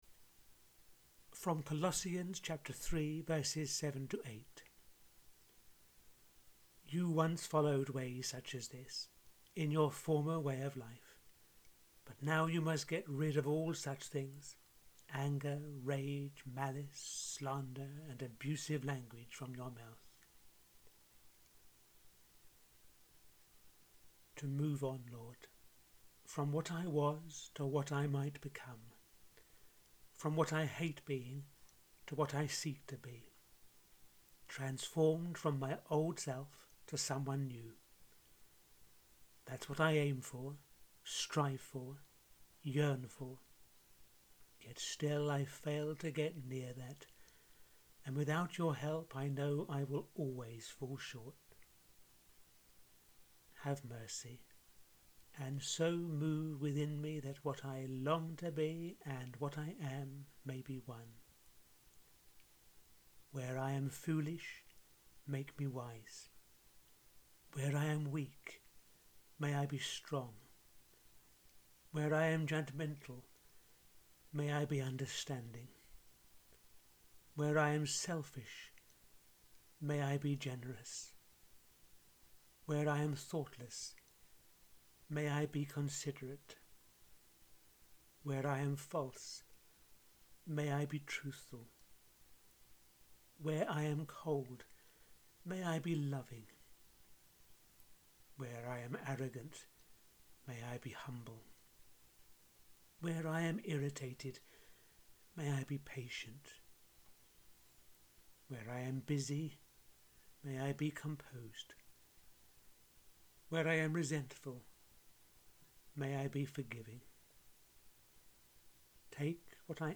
The thirteenth prayer in the audio series I’m running over these few weeks: